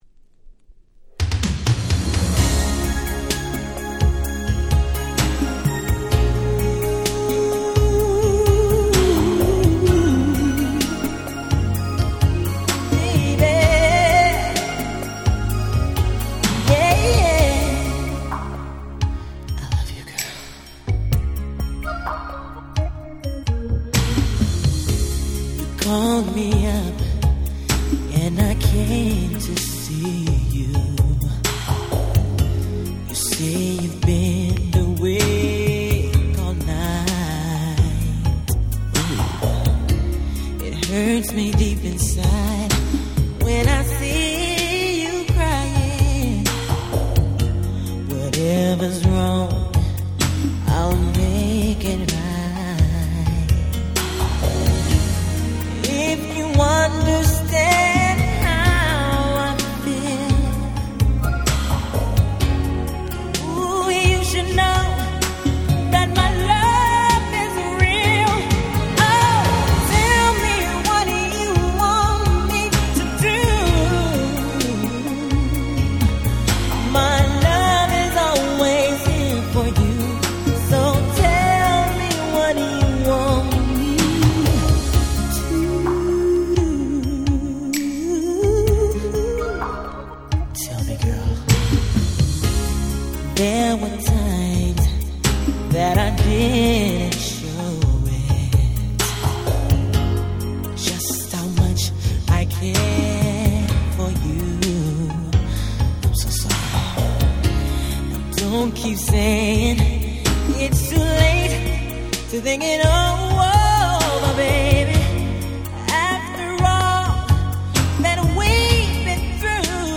91' Super Hit R&B / Slow Jam !!
まだあどけなさの残る若かりし彼が力いっぱい歌う最高のバラード！